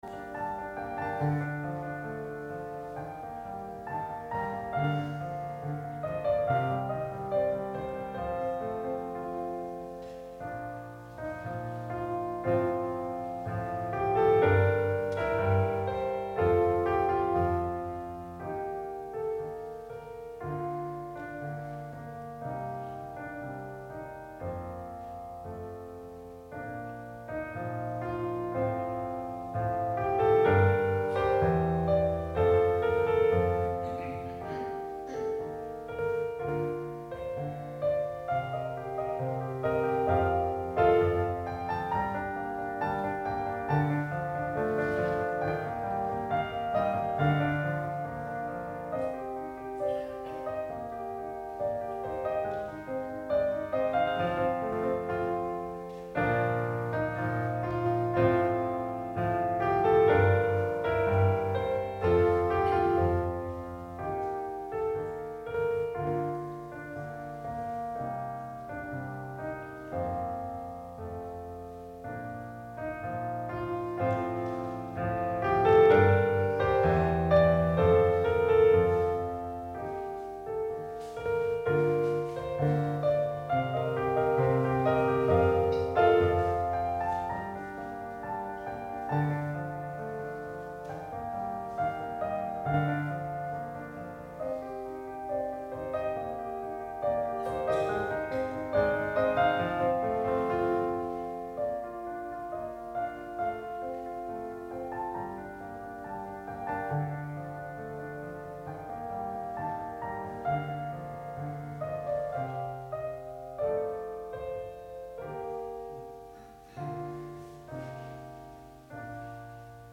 Gottesdienst - 21.09.2025 ~ Peter und Paul Gottesdienst-Podcast Podcast